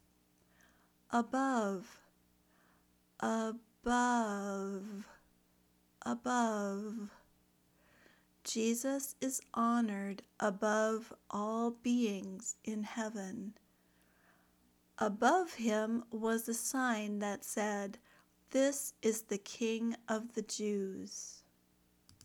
ˈbʌv/ (preposition)